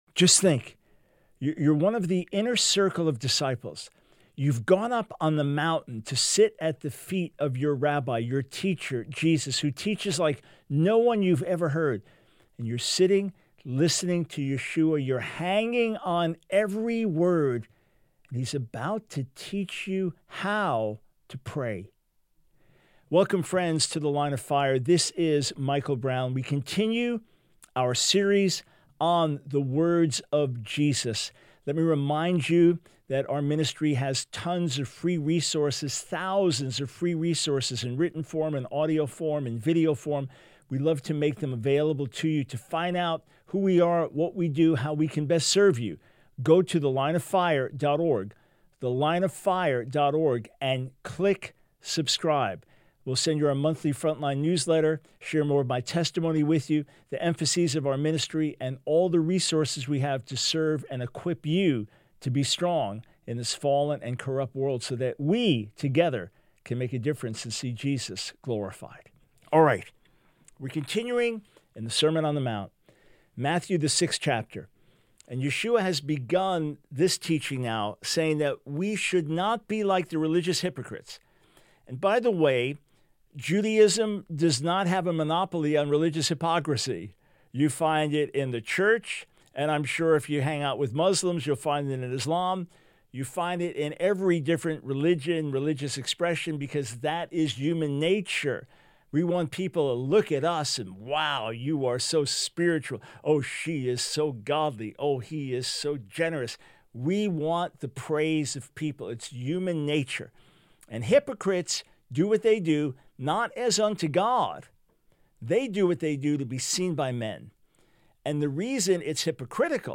Radio Broadcast